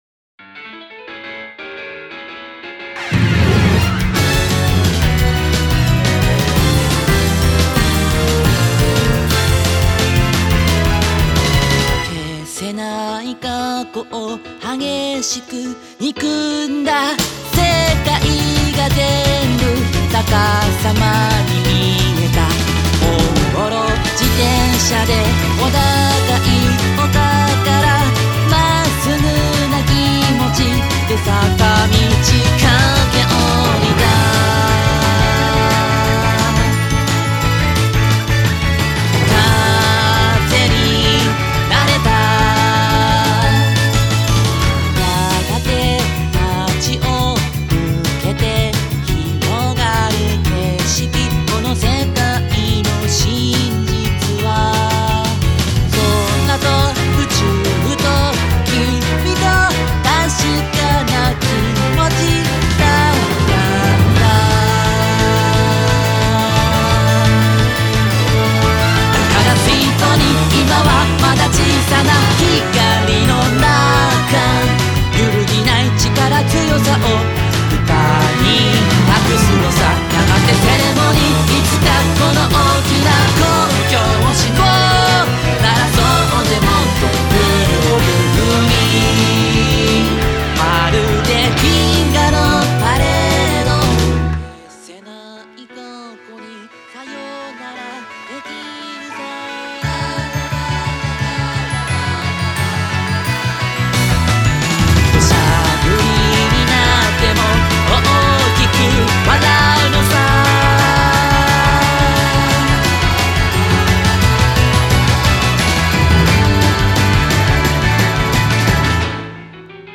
BPM88-175